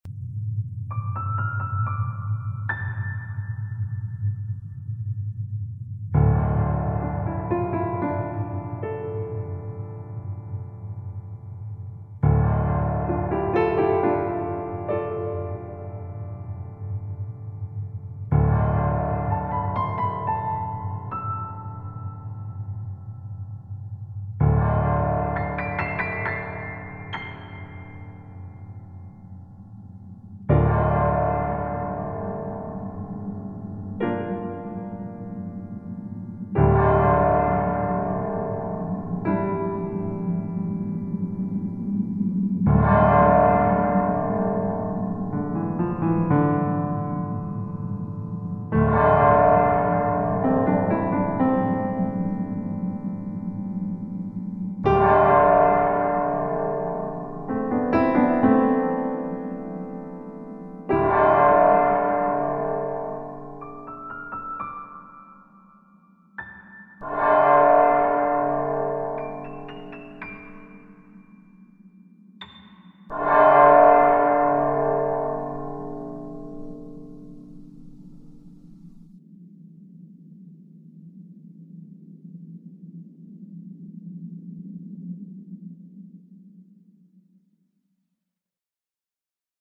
Orchestral & Instrumental Composer
The music began with a representation of a bell tolling midnight, which gradually morphed into the bell itself heard at the end of the track.  This opened to the eerie battlements scene. We kept the music sparse throughout the show to add to its simplicity and poignancy, at times introducing a small section of my Piano Prelude for One Hand, which wasn't specifically written for the show, but written for pleasure at the same time, and its themes seemed to fit perfectly.